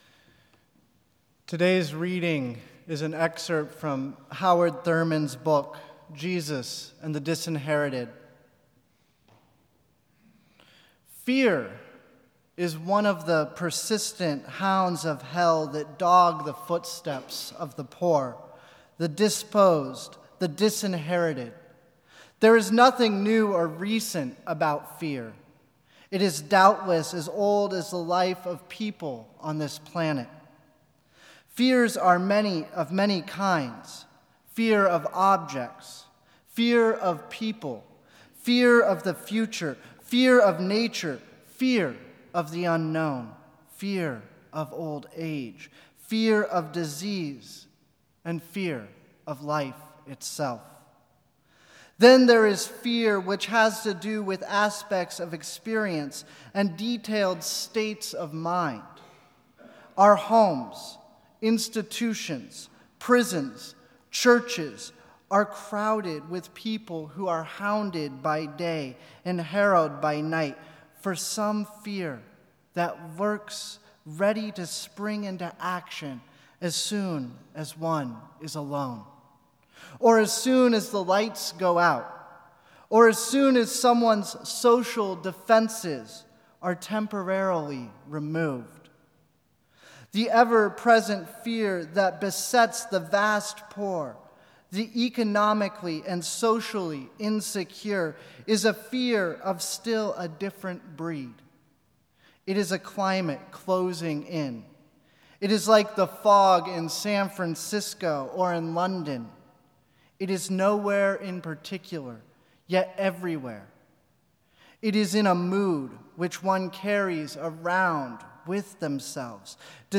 Each of us has a source of motivation that burns deep within our being—a spark that calls us into engagement with the world. This sermon will explore how we identify that source of passion within us and the importance of sharing … read more.